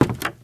STEPS Wood, Creaky, Walk 02.wav